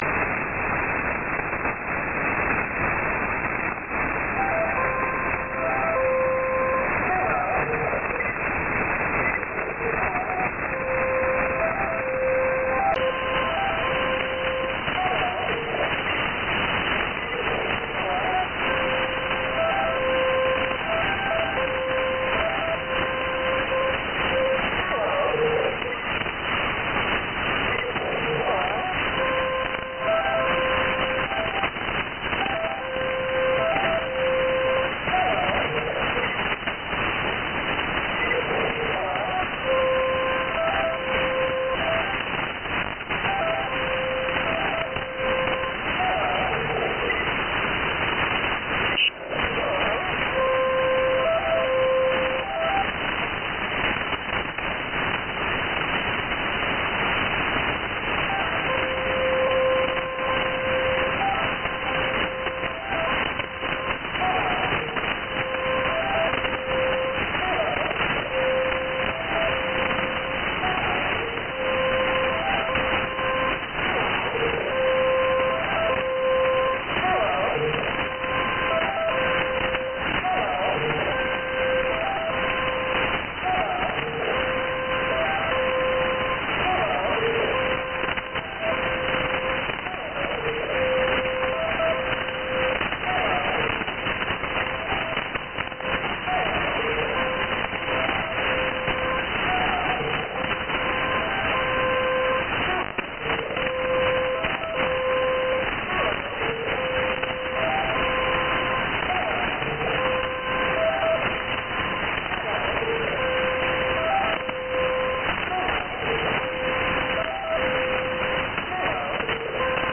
SDR recording catch. Signed on just before 2330 with repeated musical notes and speech, too weak to decipher. off 2339.